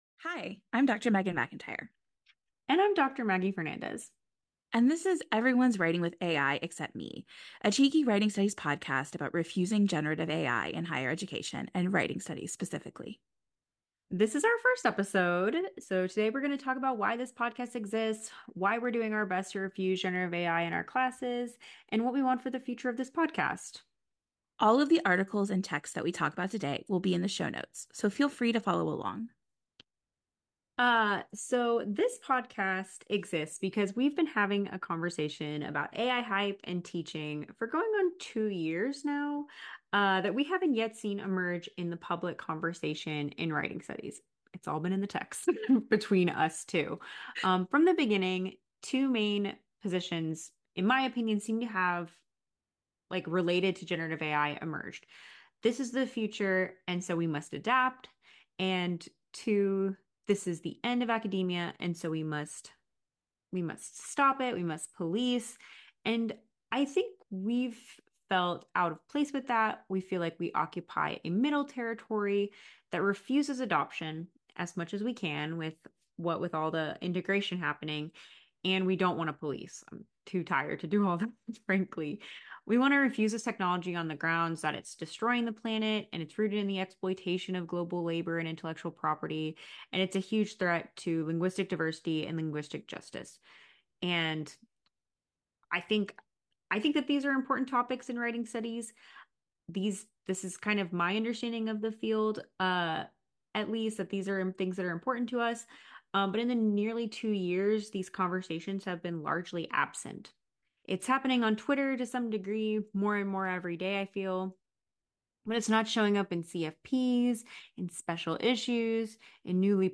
This summer (2024), we started a podcast about writing studies and generative AI.